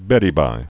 (bĕdē-bī) Informal